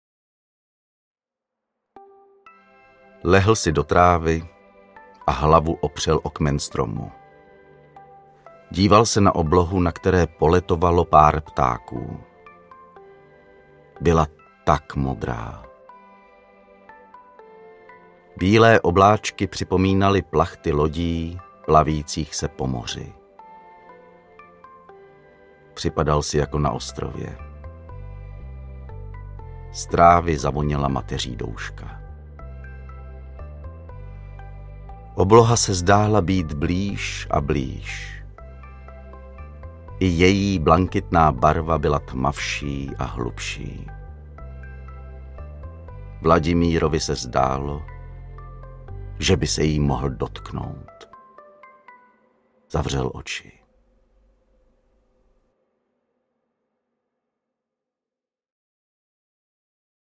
Modré z nebe audiokniha
Ukázka z knihy